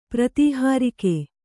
♪ pratīhārike